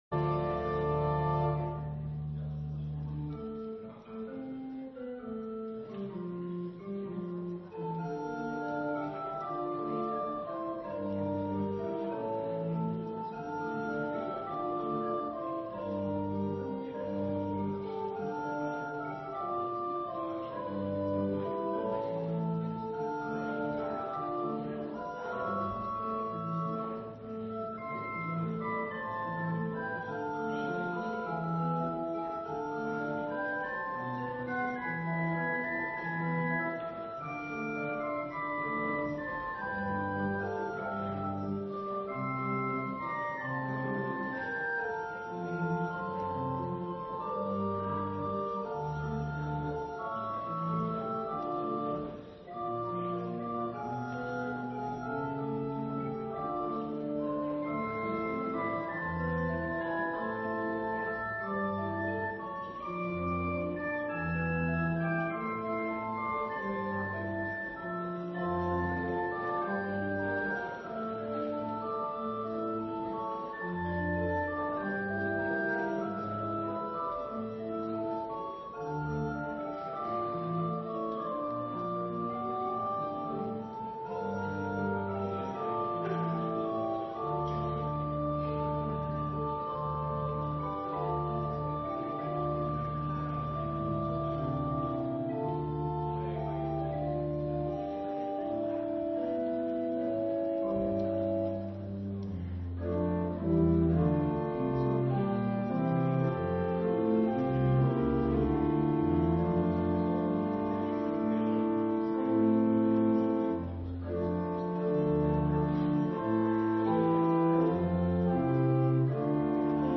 Preek over Johannes 21: 12 – Dorpskerk Abbenbroek